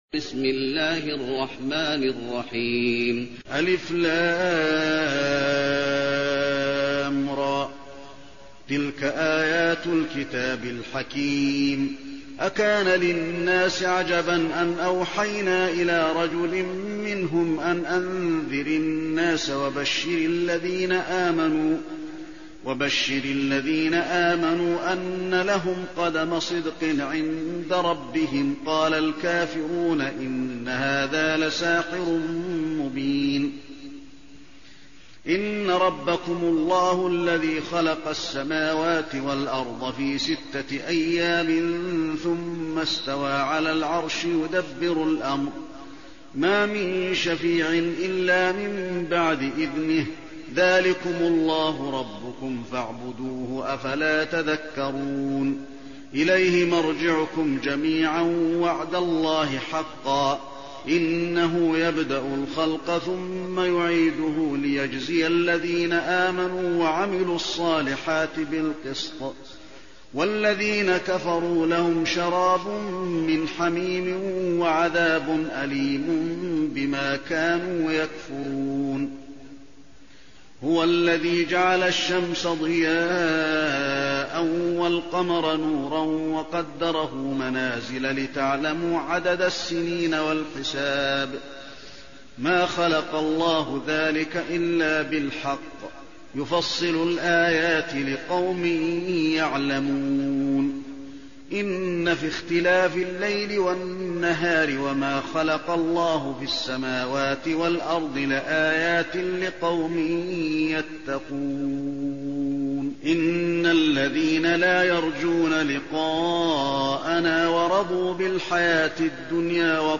المكان: المسجد النبوي يونس